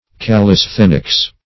\Cal`lis*then"ics\, n.